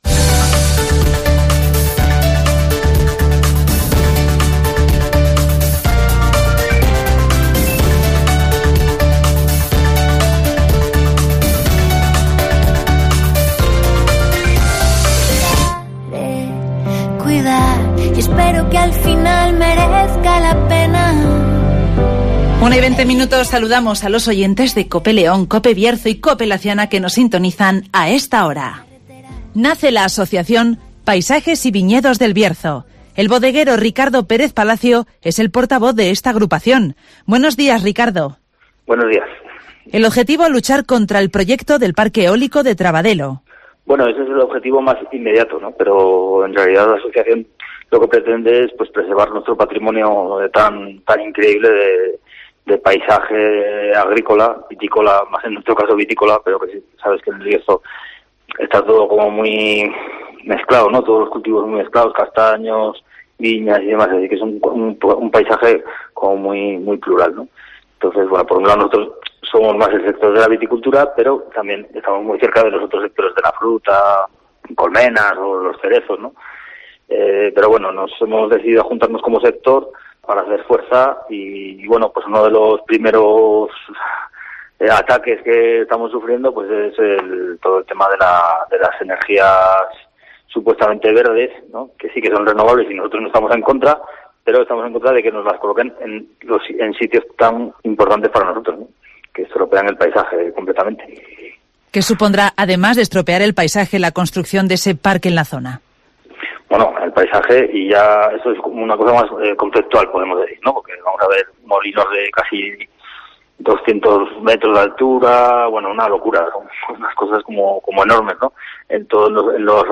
Nace la asociación Paisajes y Viñedos del Bierzo con el objetivo de parar el parque eólico de Trabadelo (Entrevista